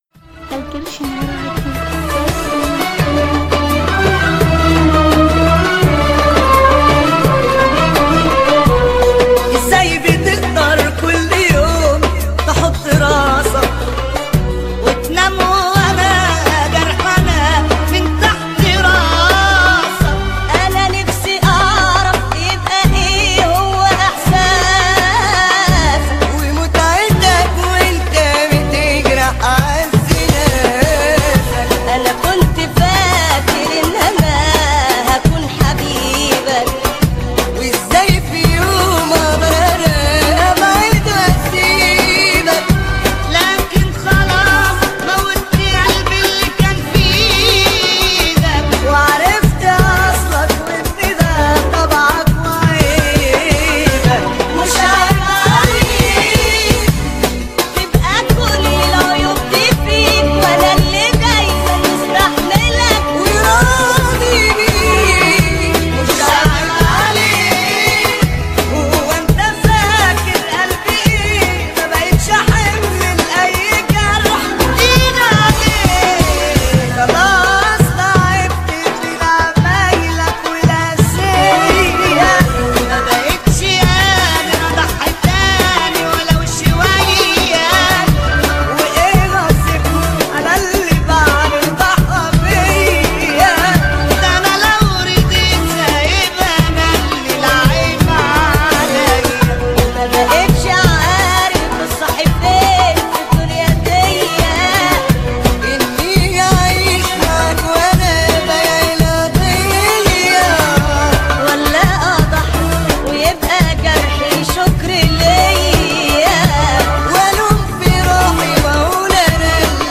اغاني مصريه حزينه